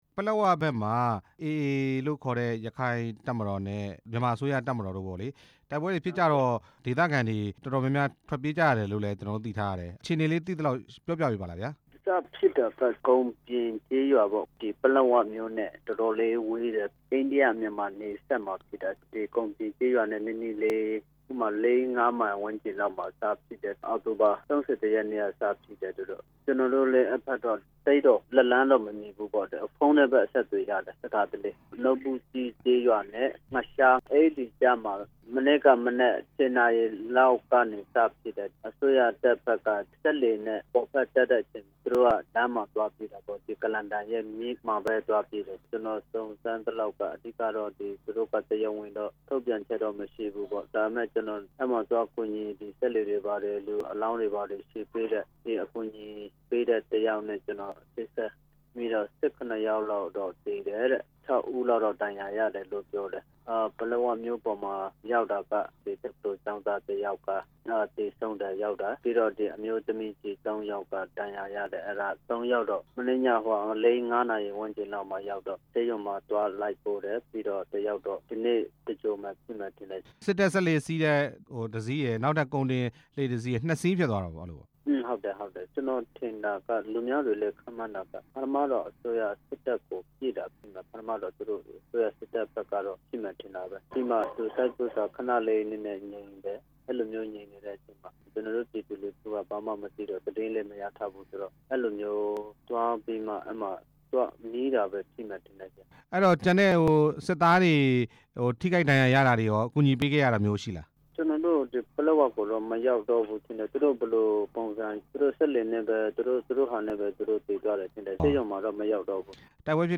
တပ်မတော်နဲ့ အေအေ တိုက်ပွဲဖြစ်တဲ့အကြောင်း မေးမြန်းချက်